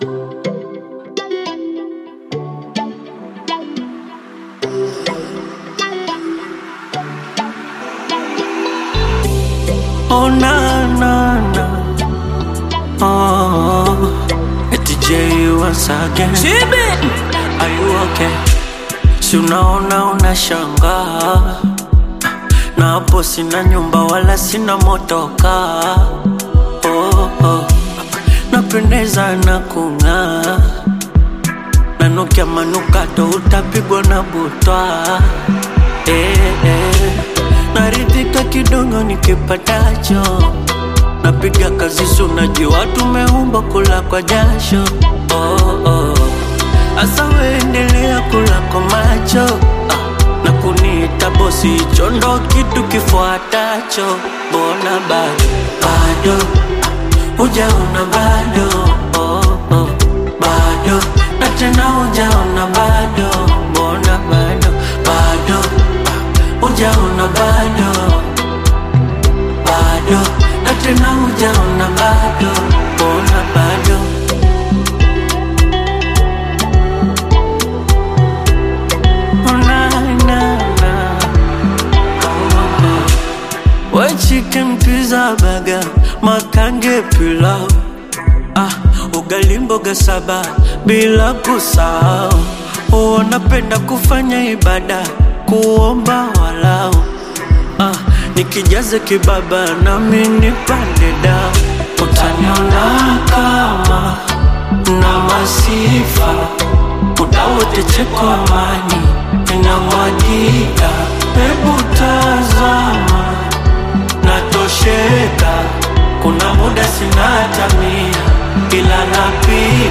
Tanzanian singer